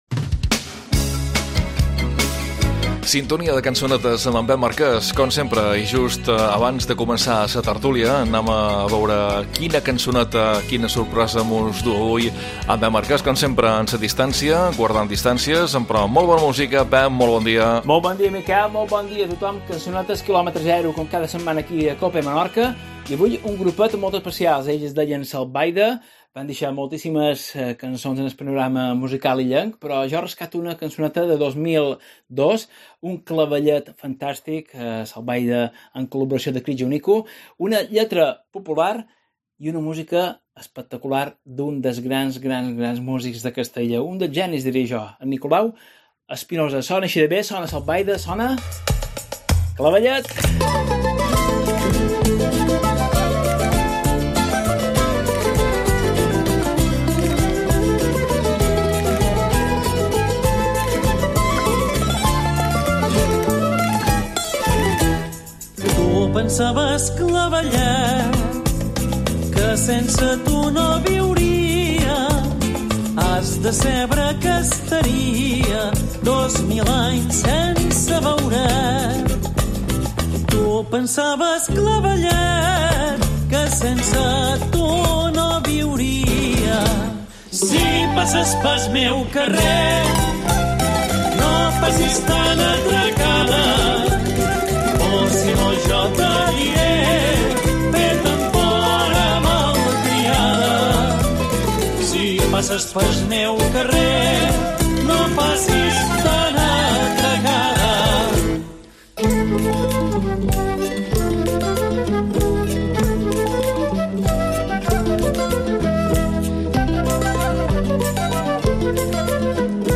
cançonetes.